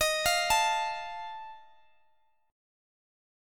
D#sus2b5 Chord (page 2)
Listen to D#sus2b5 strummed